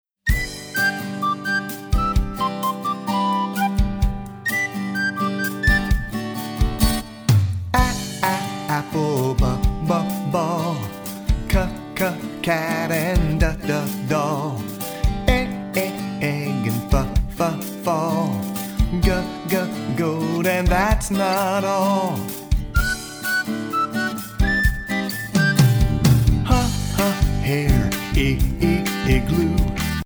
Listen to a sample of this song.